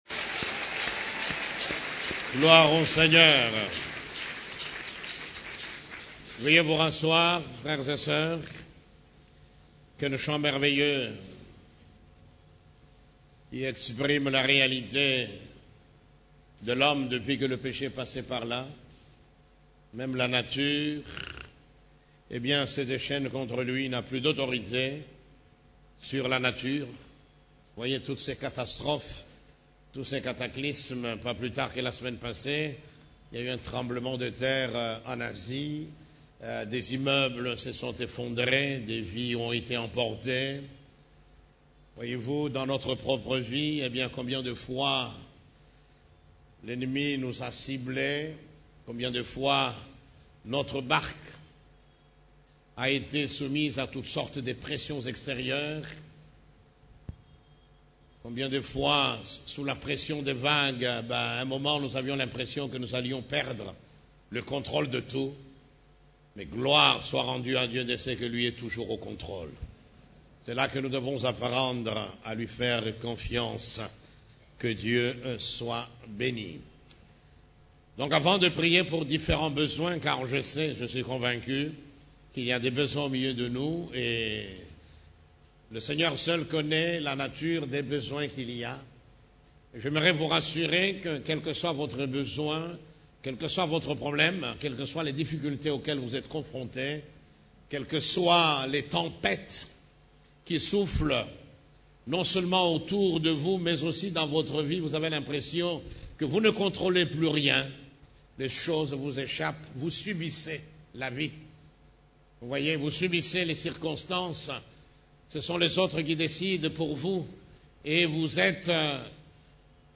CEF la Borne, Culte du Dimanche, Créés et rachetés pour régner dans cette vie (2)